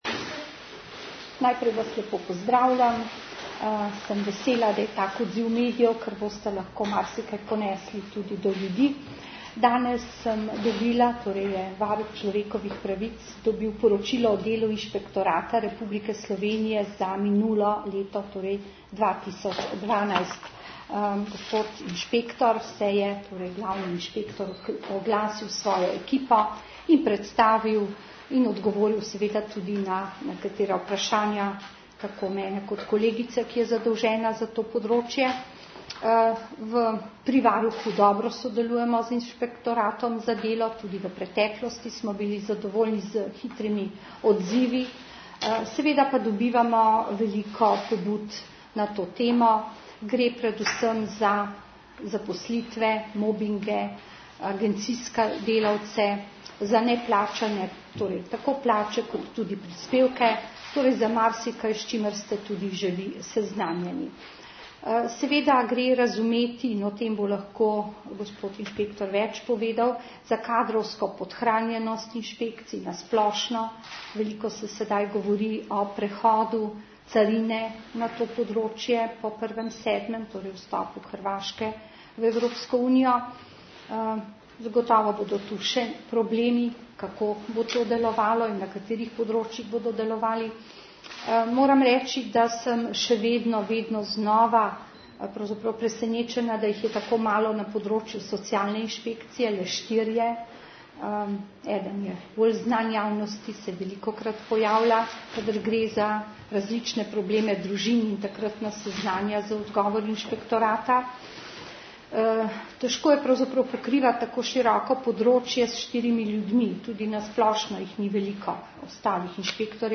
Glavni inšpektor je varuhinji ob začetku spoznavnega srečanja predal letno poročilo inšpektorata. Po srečanju sta javnost seznanila z vsebino pogovora, glavni inšpektor pa je na vprašanja novinarjev predstavil tudi nekaj poudarkov iz poročila.
Izjavo lahko v obliki zvočnega zapisa poslušate tukaj.